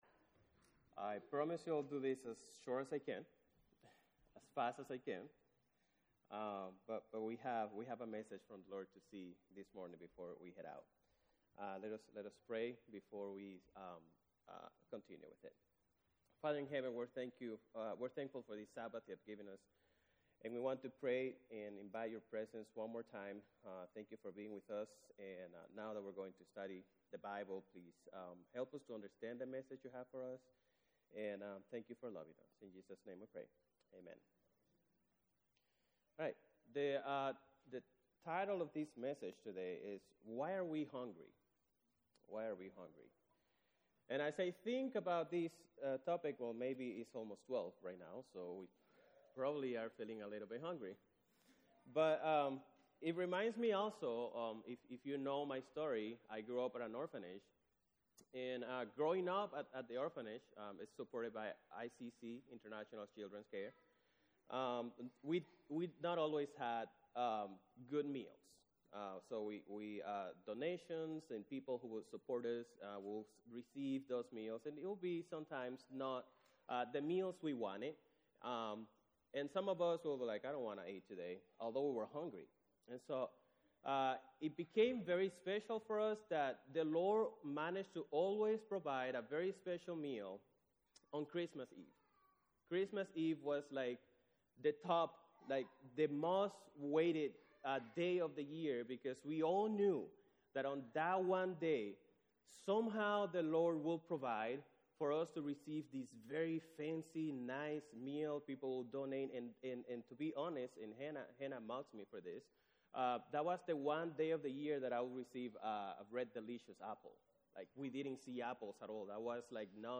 The Spoken Word